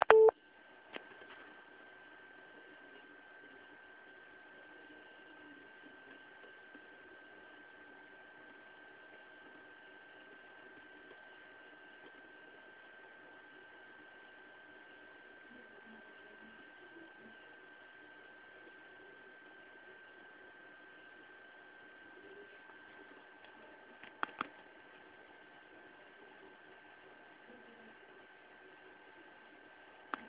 Chłodzenie obu kart jest identyczne, zajmuje dwa sloty i wyrzuca ciepłe powietrze na zewnątrz obudowy.
tu nagranie) na którym słychać głównie odgłosy reszty komputera.